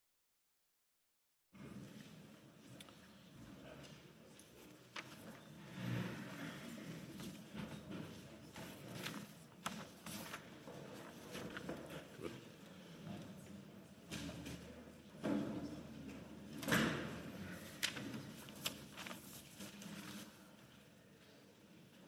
30.11.2022Wortmeldung
Session des Kantonsrates vom 28. bis 30. November 2022